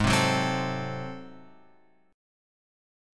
G#7b9 chord {4 3 4 5 x 5} chord